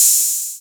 808-OpenHiHats07.wav